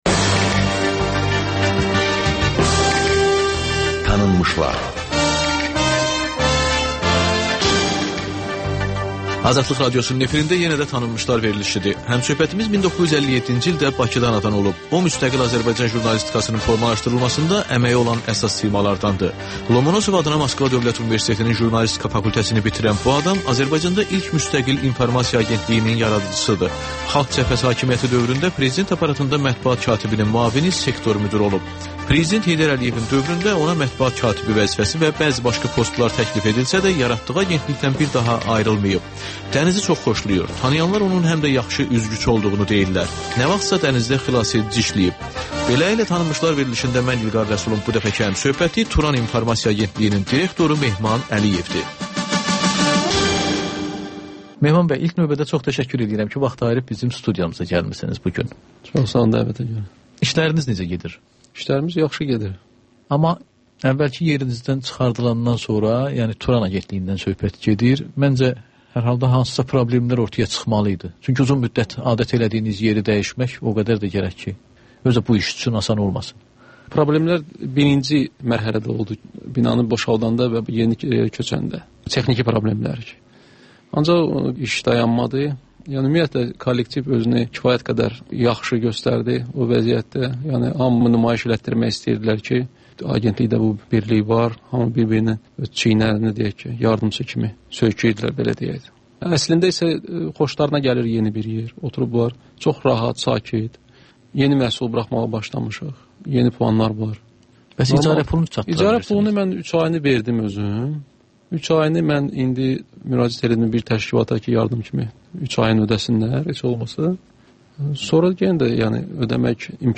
Ölkənin tanınmış simalarıyla söhbət